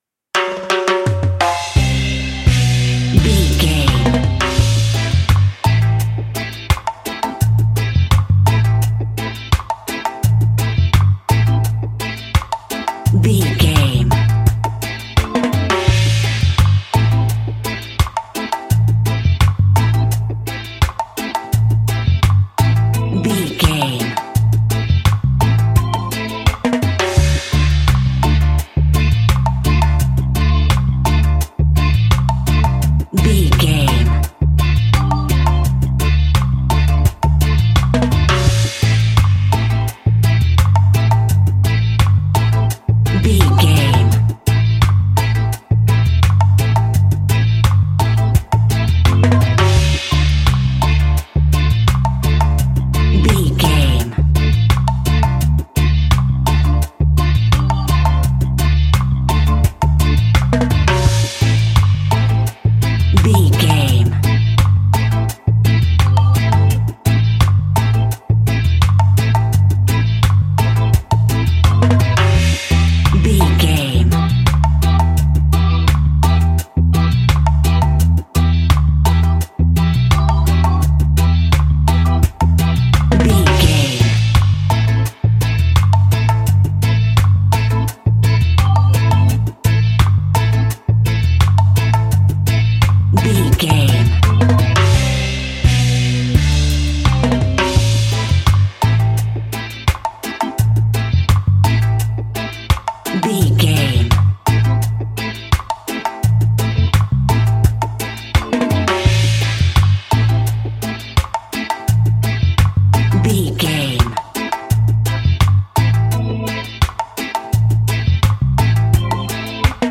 Classic reggae music with that skank bounce reggae feeling.
Aeolian/Minor
laid back
chilled
off beat
drums
skank guitar
hammond organ
percussion
horns